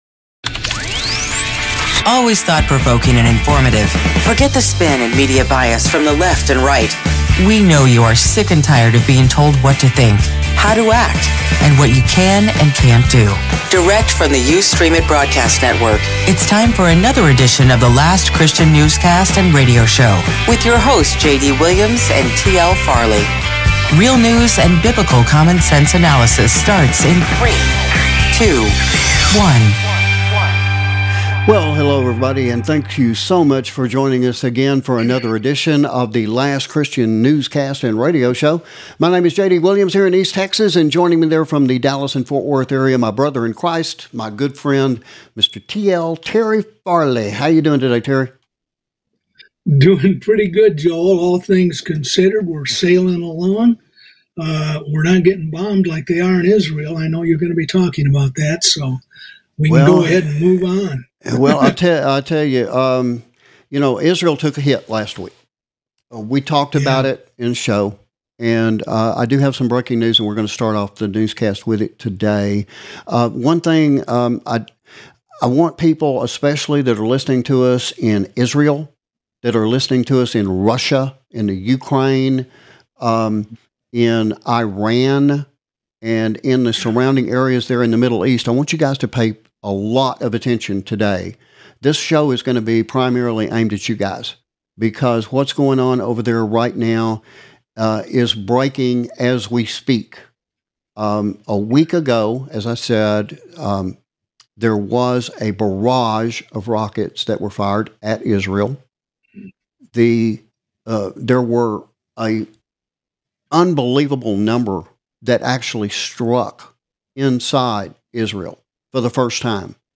LCRS Newscast & Radio Show For May 9th 2023